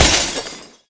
glass_break.ogg